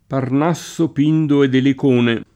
Elicona [elik1na] (raro Elicone [elik1ne]) top. m. (Gr.) — es.: Parnasso, Pindo et Elicone [